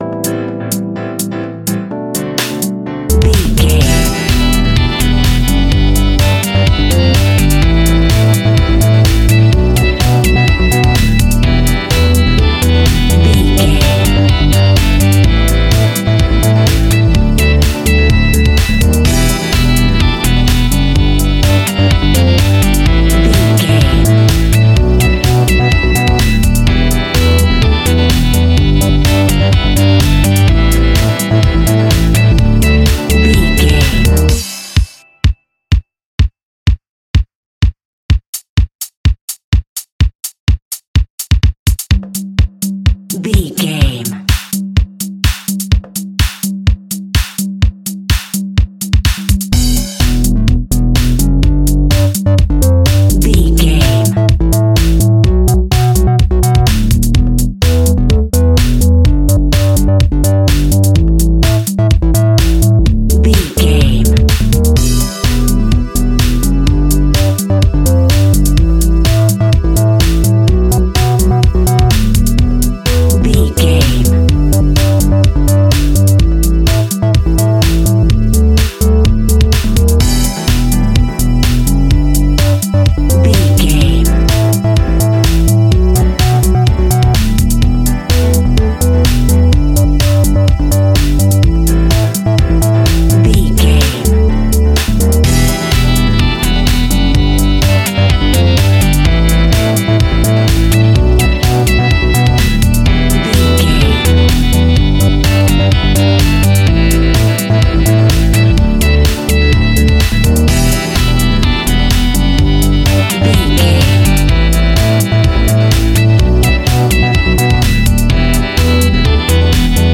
Funky House Music Cue.
Aeolian/Minor
groovy
uplifting
energetic
drums
synthesiser
electric piano
bass guitar
funky house instrumentals
funky synths